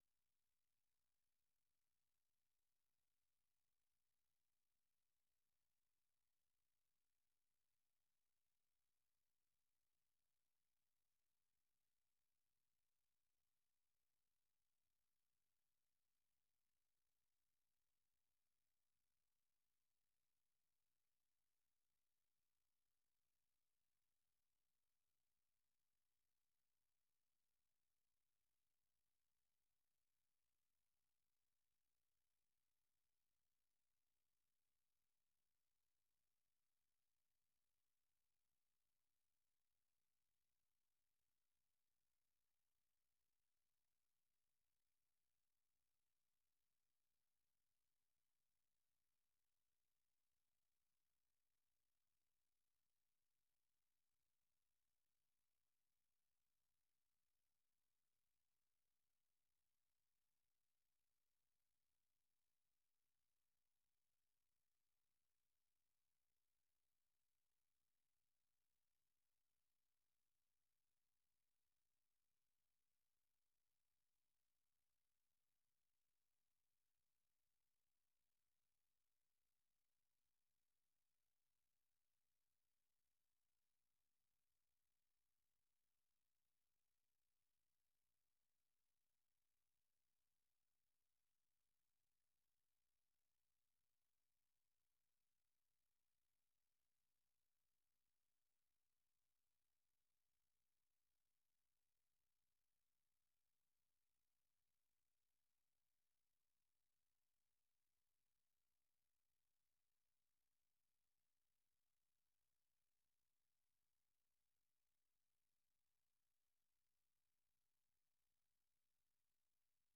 Listen Live - 粵語廣播 - 美國之音